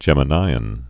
(jĕmə-nīən, -nē-)